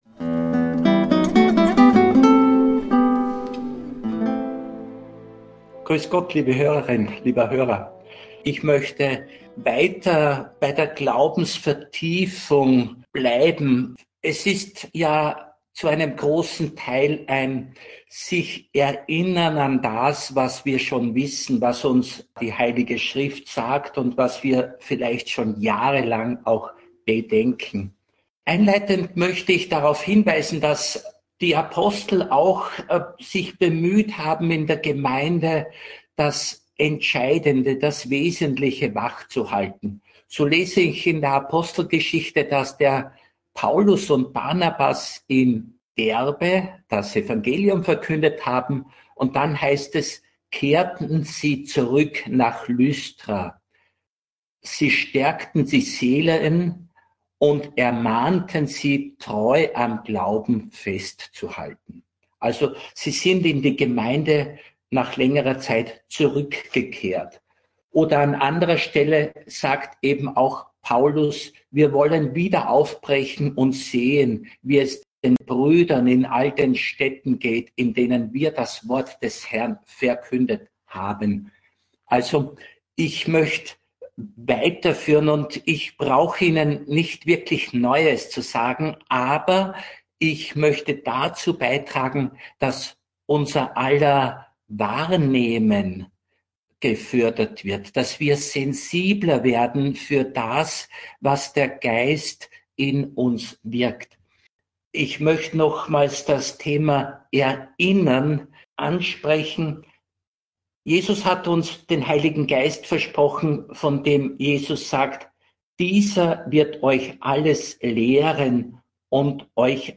(Radio Maria Aufzeichnung 3.9.2025) Mehr